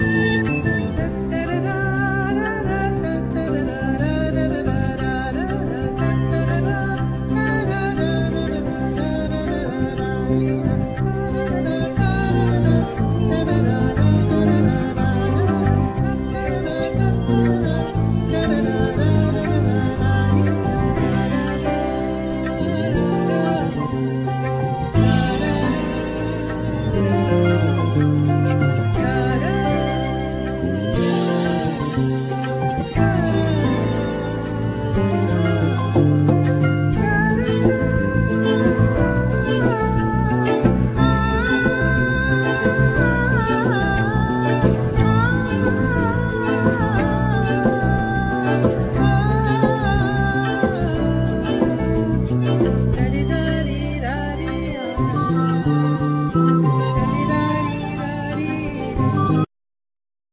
Vocals
Tabla
Piano,Keyboards
Sitar
Violin,Viola,Cello,Contra bass